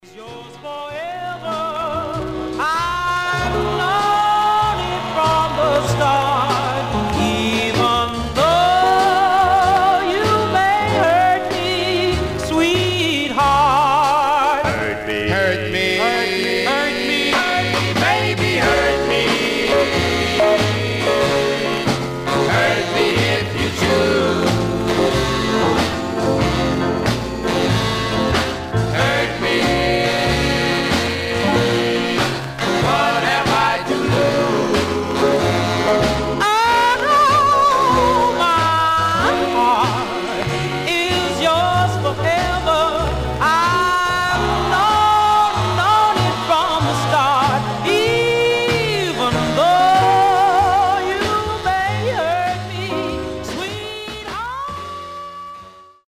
Surface noise/wear
Mono
Male Black Groups